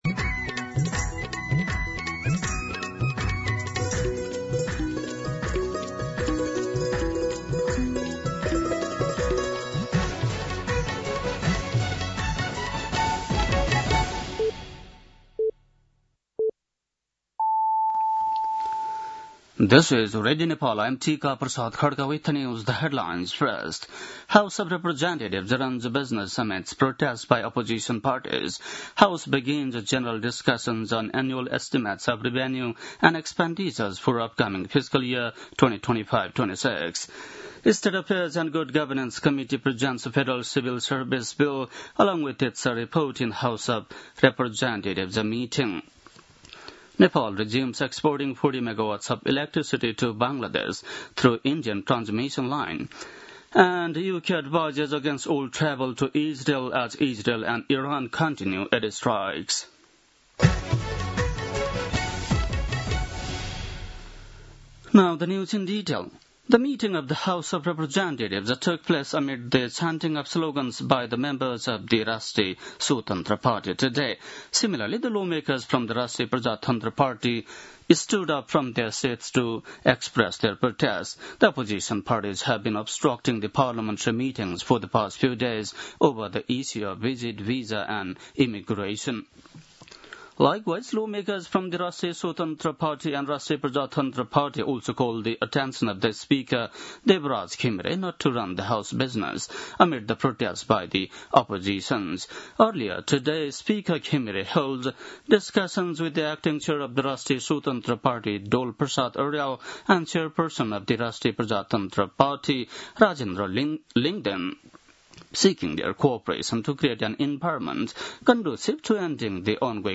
बेलुकी ८ बजेको अङ्ग्रेजी समाचार : १ असार , २०८२
8.-pm-english-news-1-2.mp3